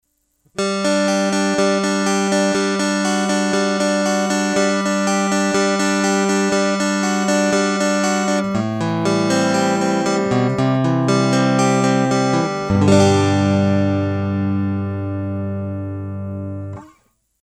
Если нужно расширять диапазон вверх - уменьшать кондесатор в темброблоке НО - это уже будет не традиционный звук страта А так сингл можно очень верхастым сделать, у меня из всяких экспериментов не всё сохранилось, но здесь вот примерно 7кГц резонанс (довольно резкий, ну уж какой есть), это DI-ник.